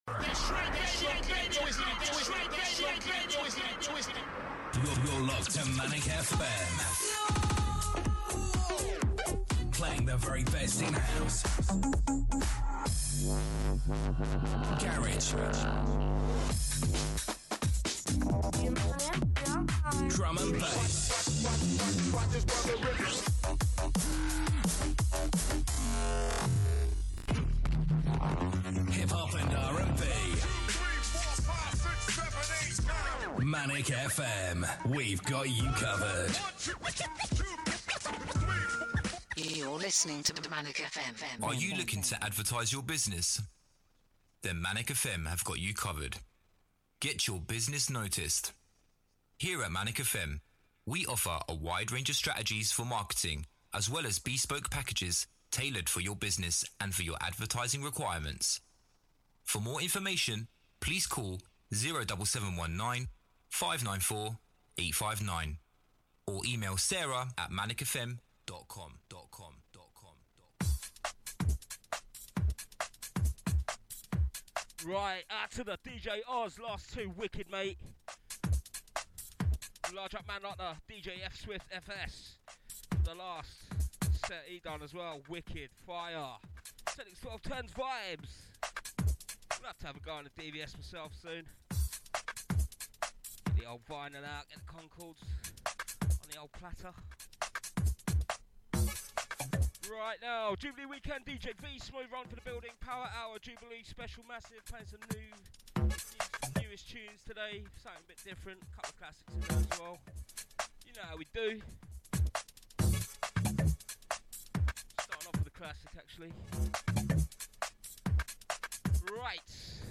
Got some real bangers lined up for this Sunday!